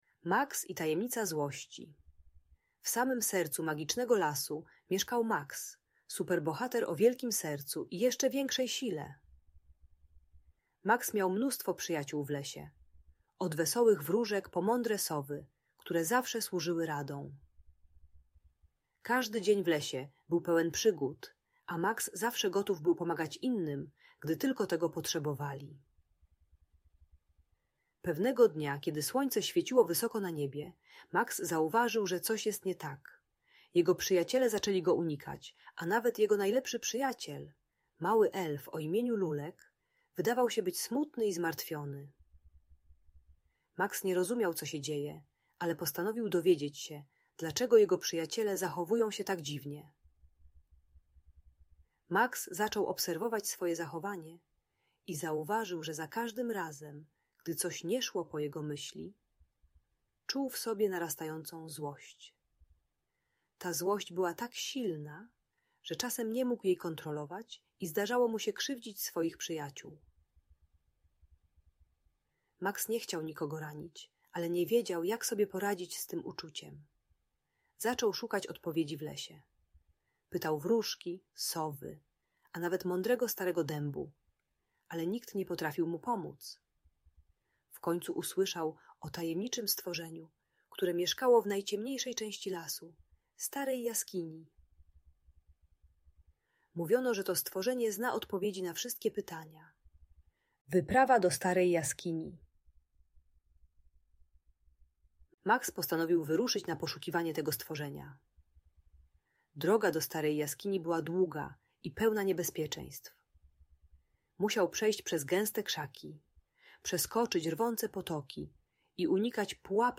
Historia Maksa - Tajemnica Złości i Przyjaźni - Audiobajka